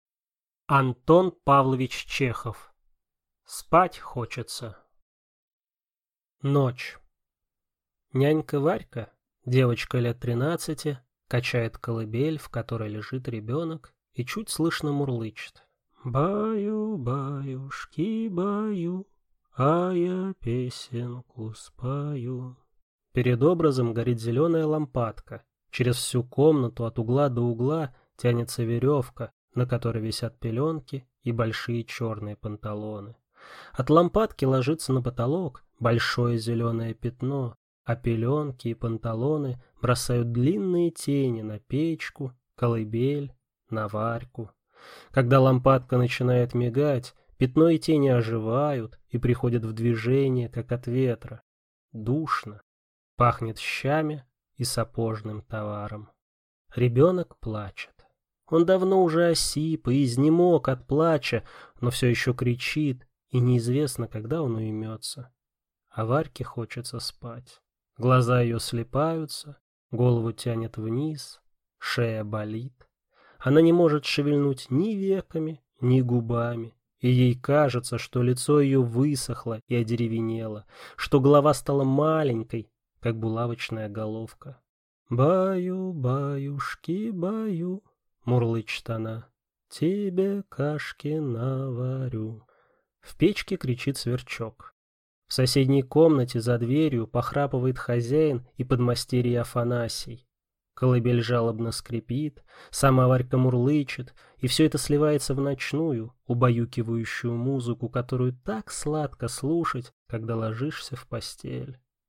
Аудиокнига Спать хочется | Библиотека аудиокниг